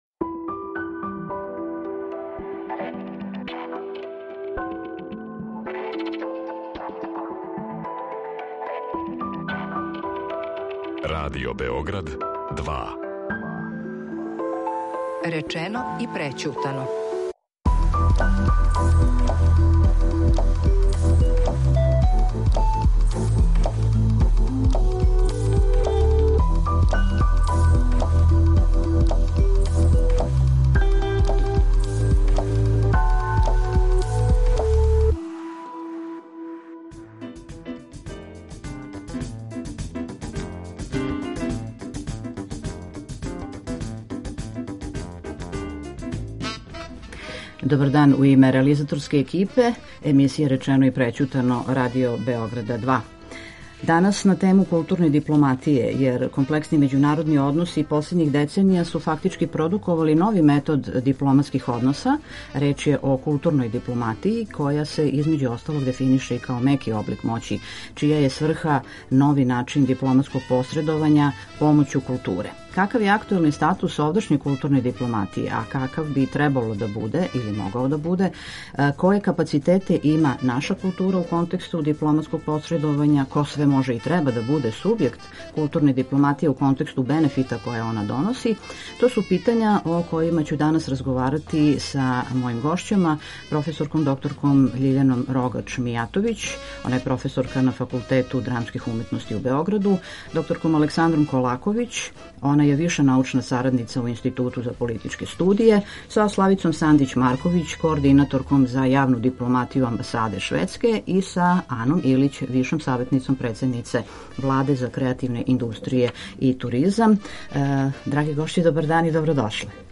Какав је актуелни статус овдашње културне дипломатије а какав би какав требало да буде, које капацитете има наша култура у контексту дипломатског посредовања, ко све може и треба да буде субјект културне дипломатије у контксту бенефита које она доноси ? О наведенм питањима у данашњој емисији ће дебатовати